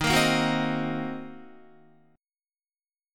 Ebm6 chord